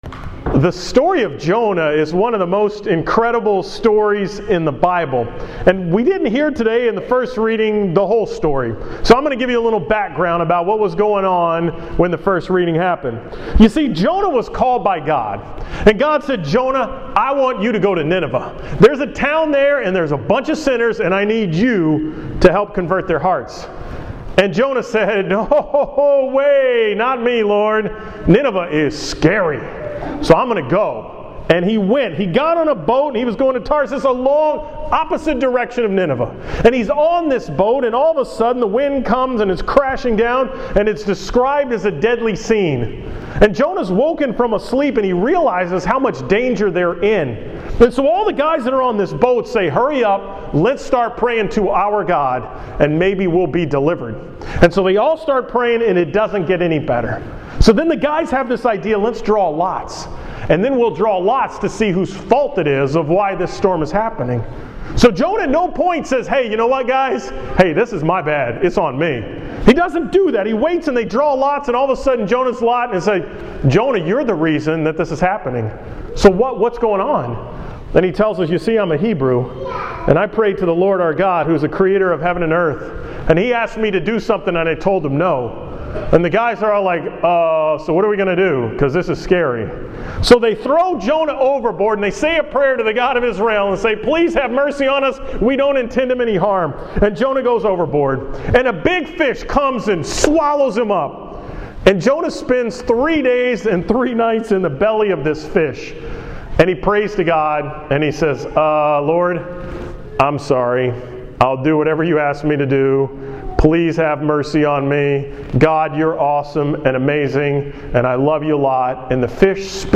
From the 10:45 Mass on January 25, 2015 at St. Thomas the Apostle in Huntsville.